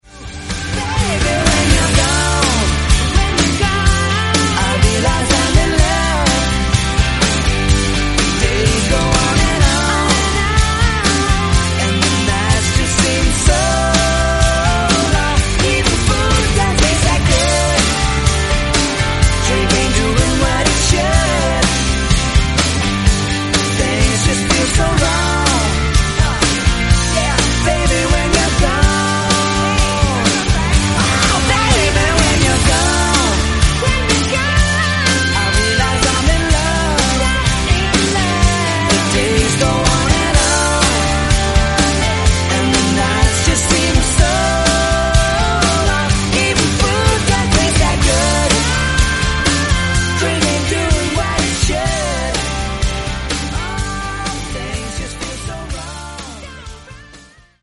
Genre: DEEP HOUSE
Clean BPM: 122 Time